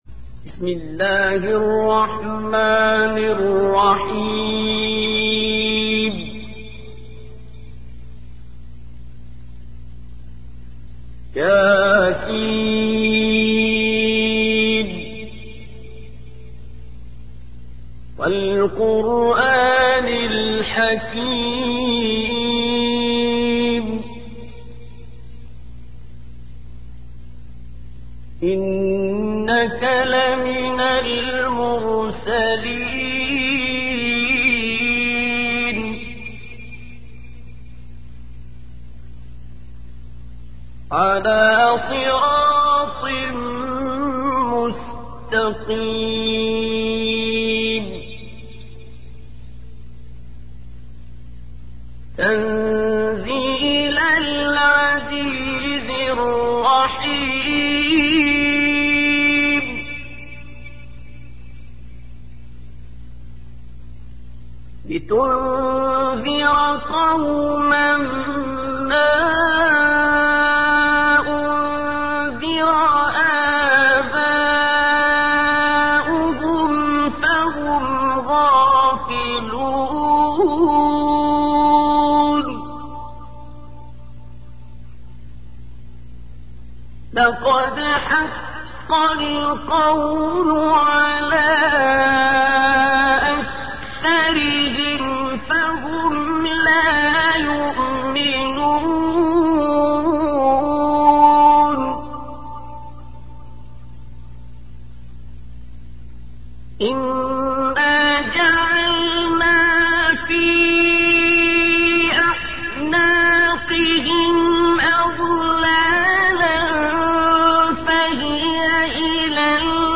دانلود تلاوت سوره مبارکه یس(یاسین) توسط عبدالباسط
تلاوت سوره مبارکه یس(یاسین) عبدالباسط جهت دانلود و استفاده ی قرآن دوستان گرانقدر آماده شده است.